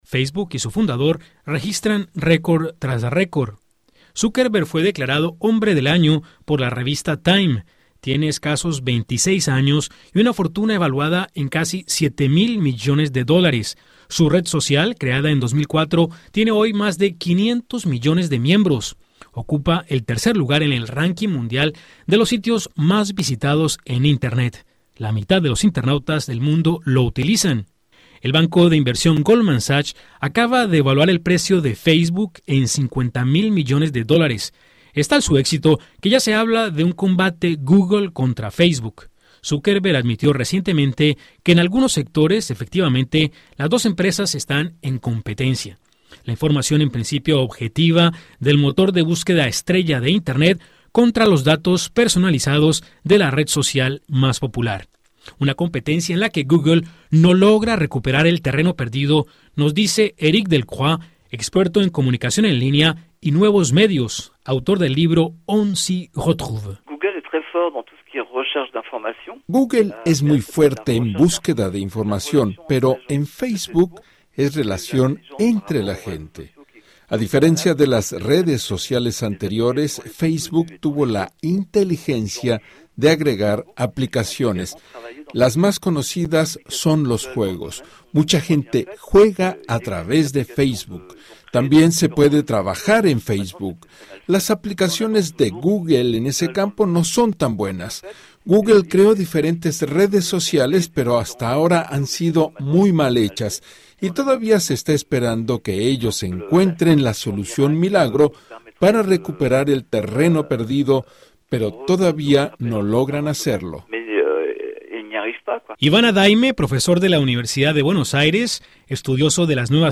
Con más de 500.000 millones de miembros, Facebook se convirtió en el último fenómeno comunicacional. Escuche el informe de Radio Francia Internacional.